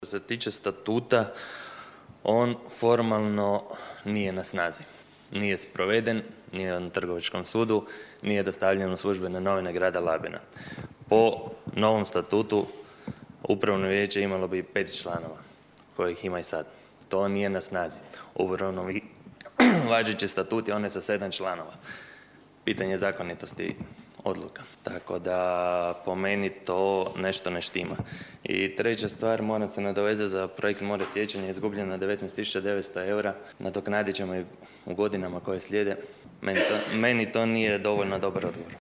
Gradsko vijeće Labina nije na današnjoj sjednici prihvatilo Izvještaj o radu Pučkog otvorenog učilišta za 2024. godinu.
Nino Bažon ponovno je kazao kako se izvještaj o radu ne može prihvatiti bez financijskog izvješća: (